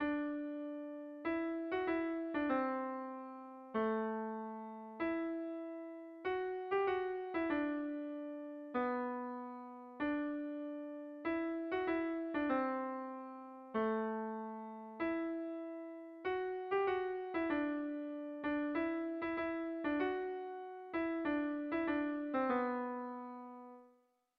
Kontakizunezkoa
7 / 6A / 7 / 6A / 6A / 6A (hg) | 13A / 13A / 6A / 6A (ip)
AAB